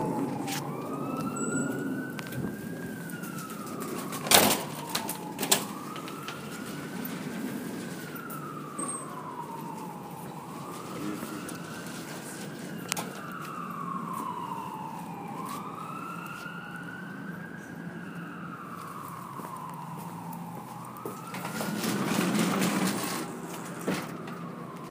Field Recording 5
Siren in the distance, doors sliding open and closed
Siren.mp3